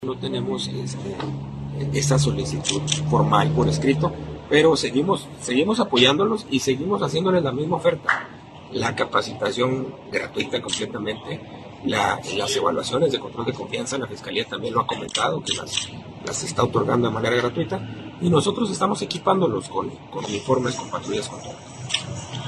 AUDIO: GILBERTO LOYA , TITULAR DE LA SECRETARÍA DE SEGURIDAD PÚBLICA DEL ESTADO (SSPE) Chihuahua, Chih.- El secretario de Seguridad Pública del Estado, Gilberto loya , informó que 11 municipios no cuentan con cuerpos de seguridad local, y estos no han lanzado convocatorias para la conformación de direcciones municipales de seguridad , por lo que la tareas las realiza la Policía del Estado bajo el esquema de Mando Único.